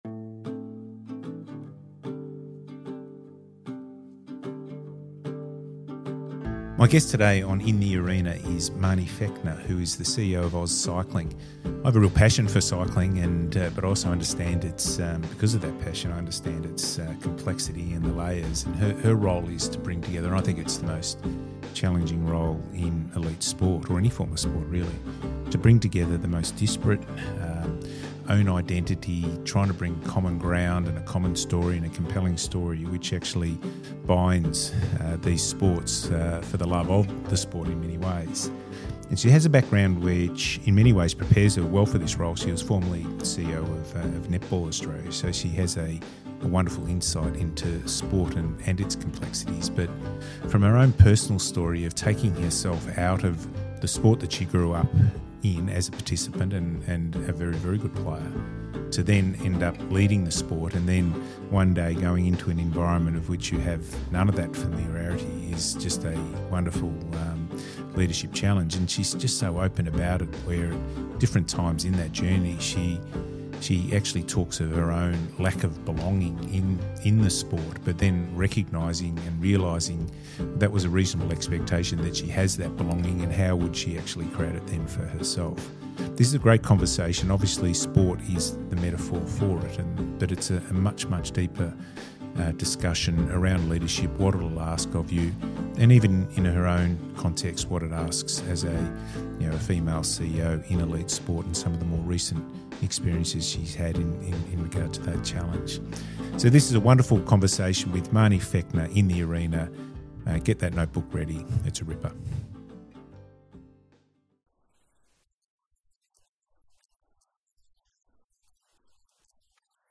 my guest on Episode 10 of ‘In the Arena’.This is a different conversation. We explore together.